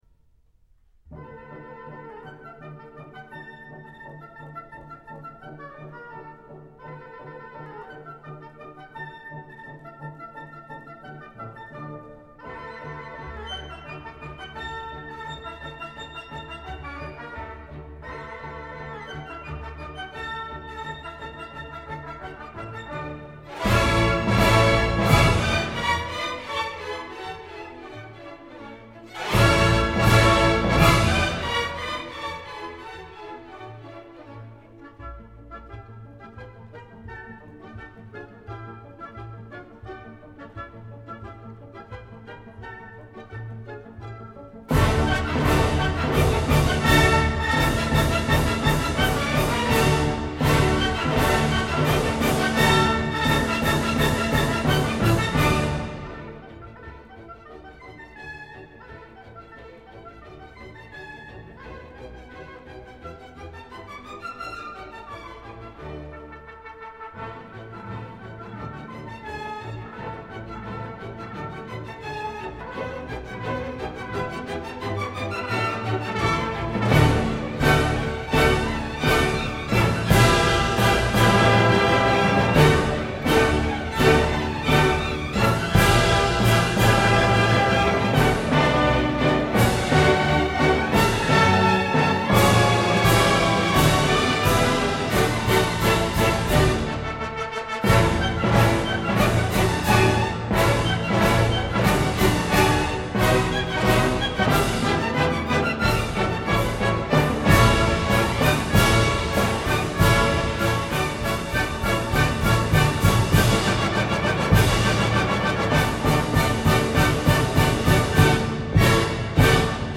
音乐类型：古典音乐 / 烂漫主义 / 管弦乐